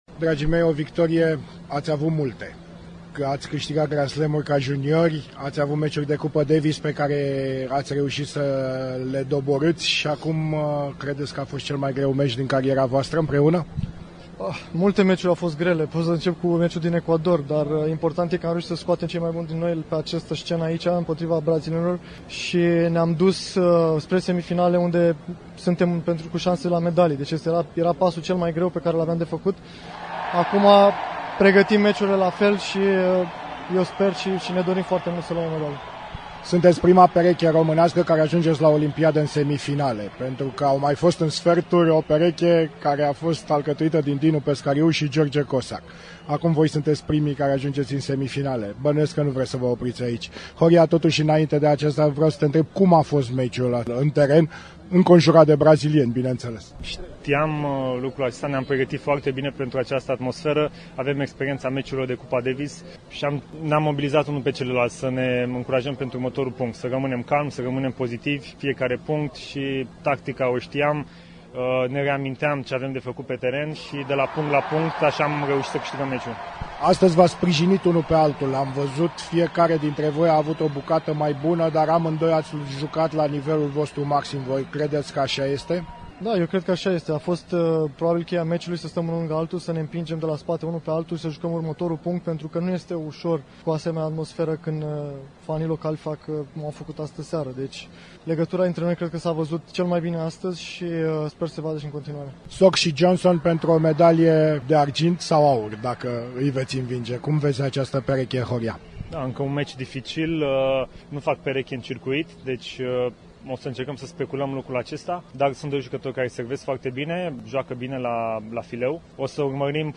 Un interviu audio cu cei doi tenismeni